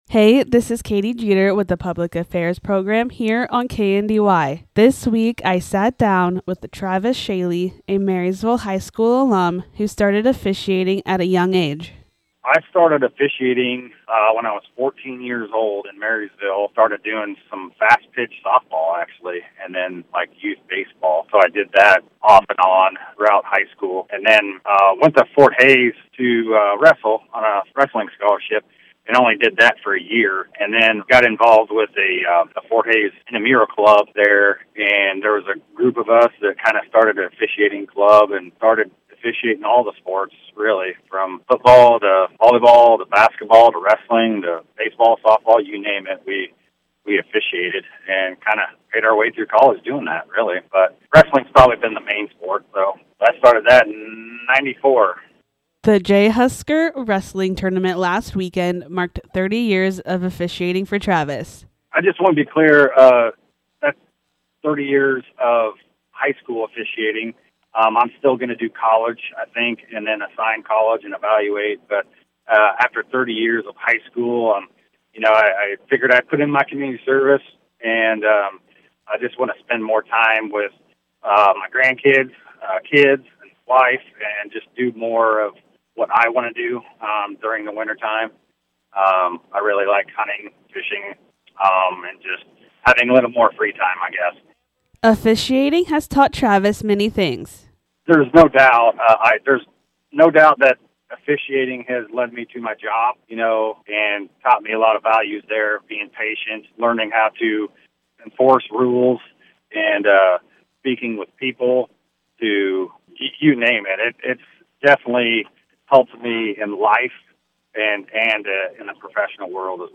Public Affairs